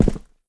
jumpland1_22.wav